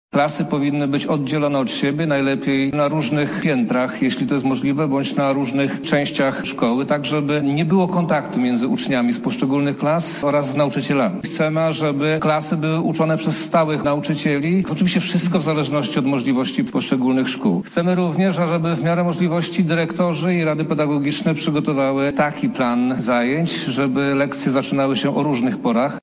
Jesteśmy przygotowani do powrotu do nauczania stacjonarnego– mówi minister edukacji i nauki Przemysław Czarnek: